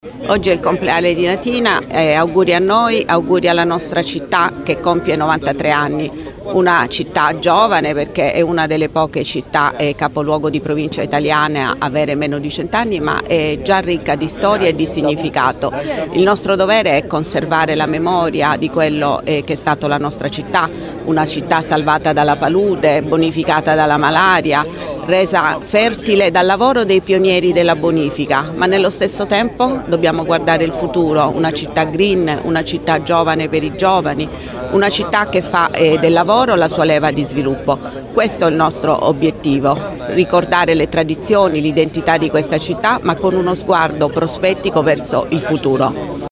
CELENTANO-INTERVISTA.mp3